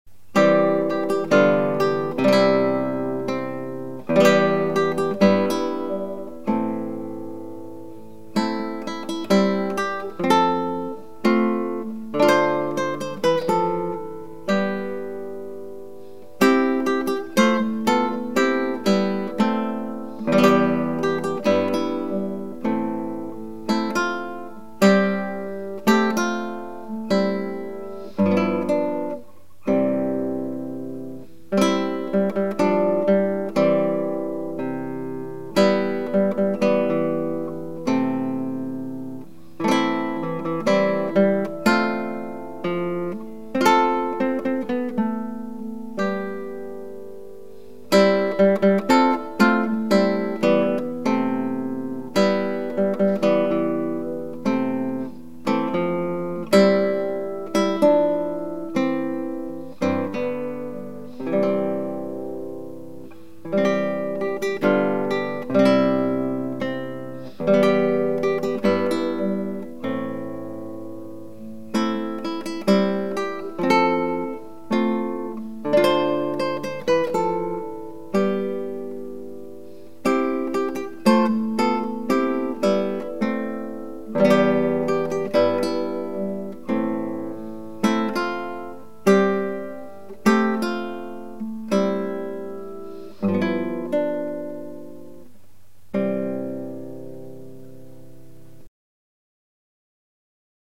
DIGITAL SHEET MUSIC - FINGERPICKING SOLO
Guitar Solo